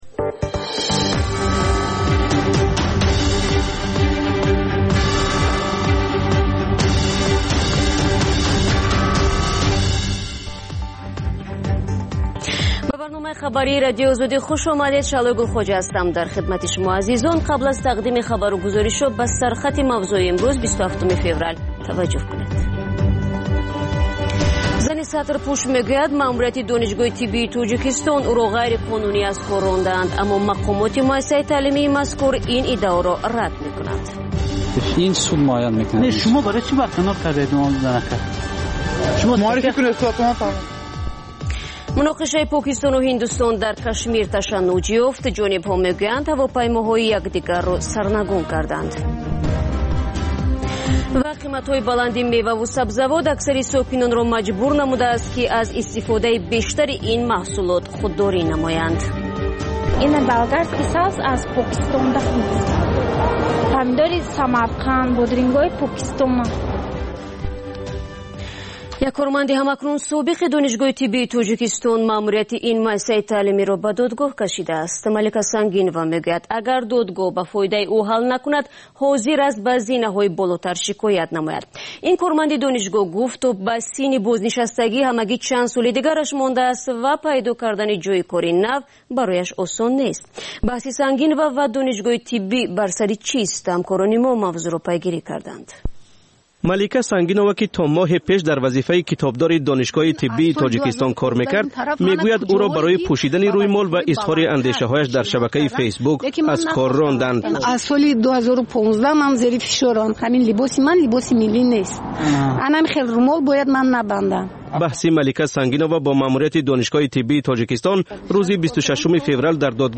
Тозатарин ахбор ва гузоришҳои марбут ба Тоҷикистон, минтақа ва ҷаҳон дар маҷаллаи бомдодии Радиои Озодӣ.